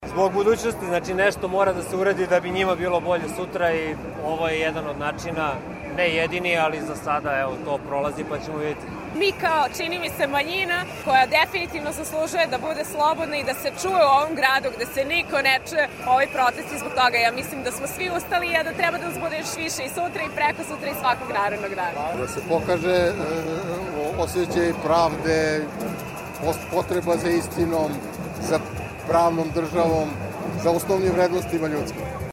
Učesnici protesta protiv rušenja u Savamali
Dok su učesnici šetnje, praćeni dobošarima išli ka zgradi Vlade, sa mnogih balkona su ih pozdravljali Beograđani.